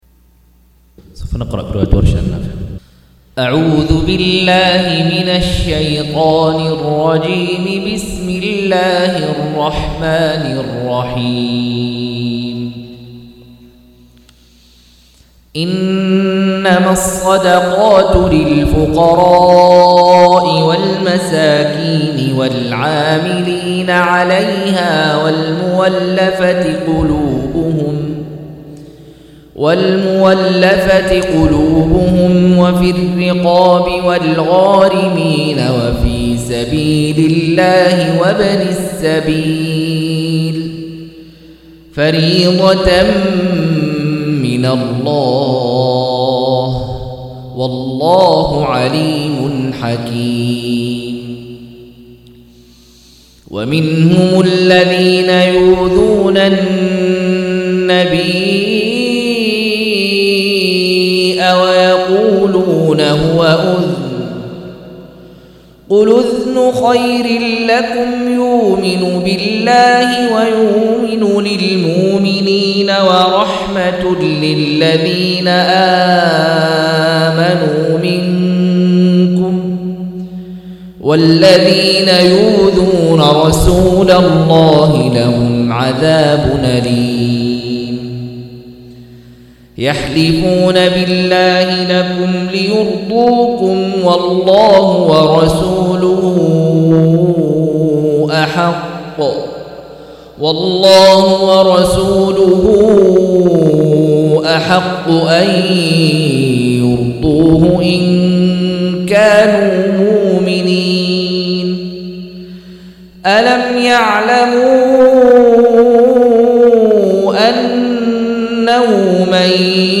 186- عمدة التفسير عن الحافظ ابن كثير رحمه الله للعلامة أحمد شاكر رحمه الله – قراءة وتعليق –